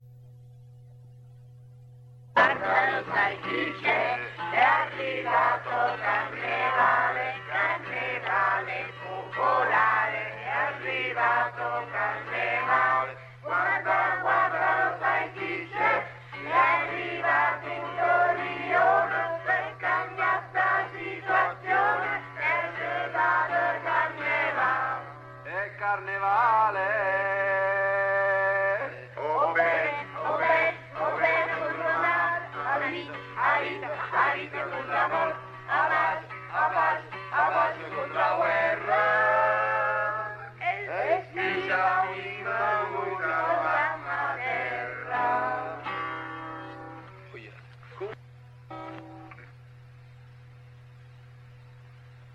1° Carnevale